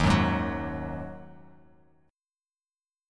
D#mbb5 chord